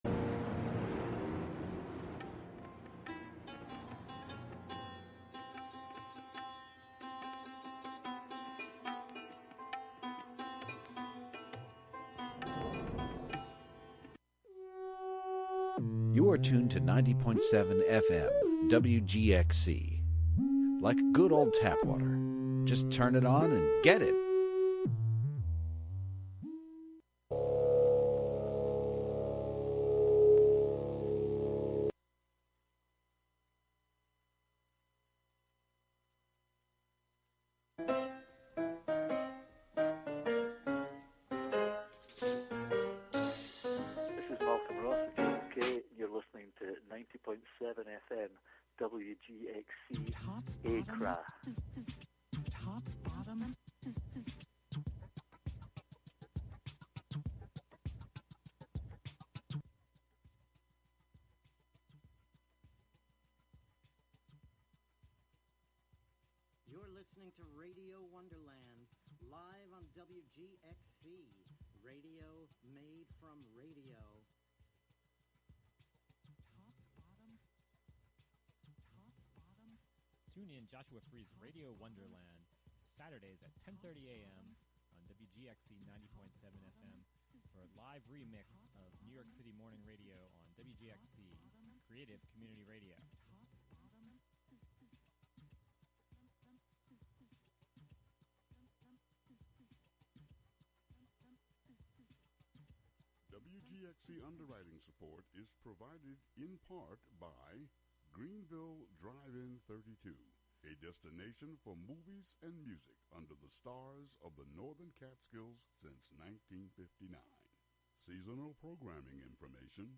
"Suddering Words" is a monthly improvised mashup of various radio show formats from rock jock to radio drama to NPR to old time mysteries and everything in between. It is unattainable gibberish in its perfect form. There will be songs, news, stories, manipulated cassettes, and some in-studio visits by demons AND angels, and it all takes place in a village of airwaves dominated by slittering sounds and suddering words.